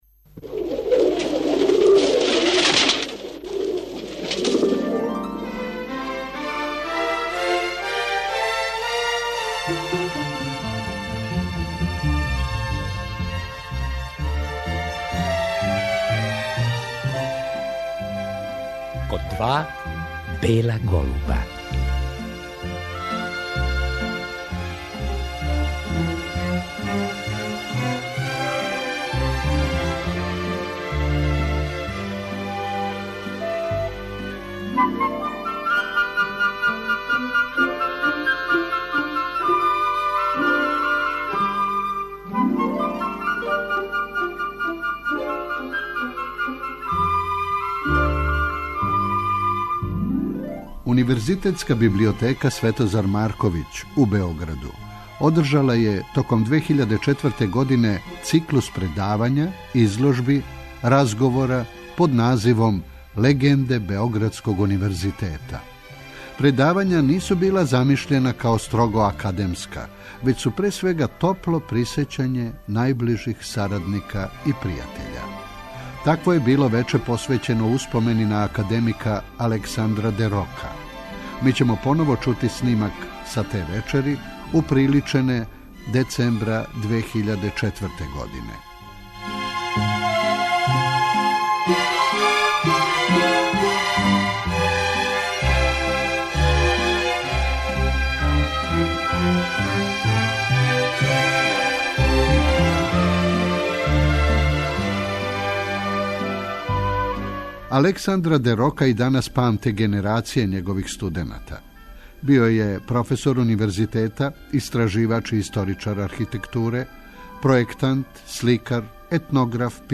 У Универзитетској библиотеци 'Светозар Марковић' у Београду, током 2004. године одржан је циклус предавања, изложби, разговора под називом ЛЕГЕНДЕ БЕОГРАДСКОГ УНИВЕРЗИТЕТА.
Чућемо поново снимак са те вечери, уприличене децембра 2004. године.